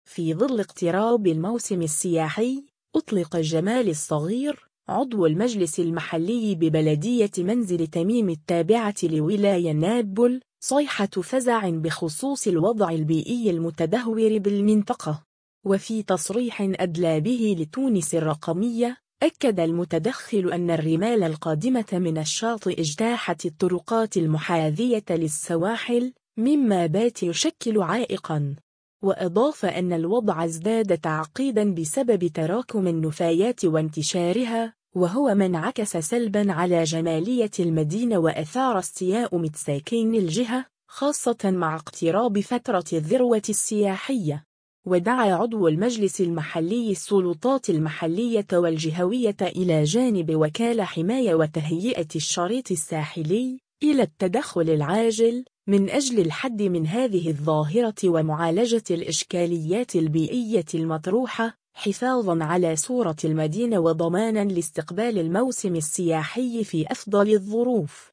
وفي تصريح أدلى به لتونس الرقمية ، أكد المتدخل أن الرمال القادمة من الشاطئ اجتاحت الطرقات المحاذية للسواحل، مما بات يشكل عائقاً.